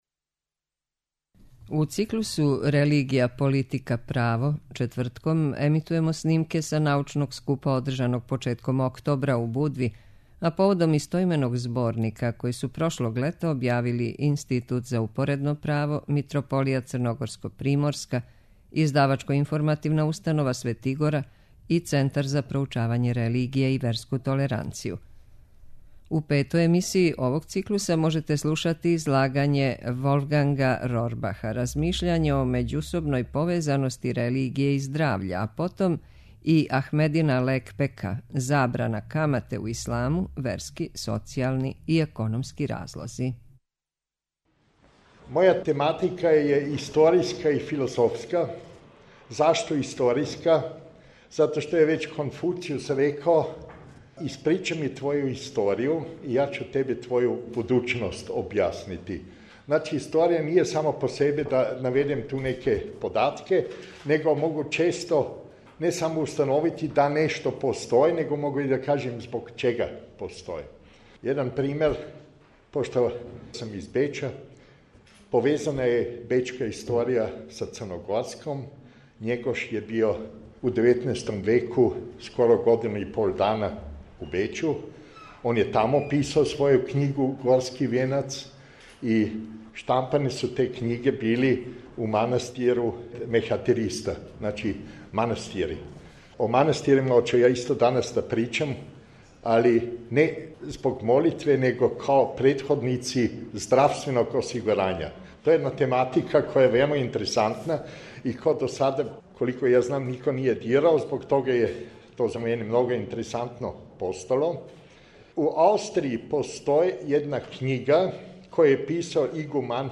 У циклусу РЕЛИГИЈА, ПОЛИТИКА, ПРАВО четвртком емитујемо снимке са међународног научног скупа одржаног поводом представљања истоименог зборника научних радова.
Међународни научни скуп поводом представљања овог зборника одржан је од 1. до 4. октобра у Будви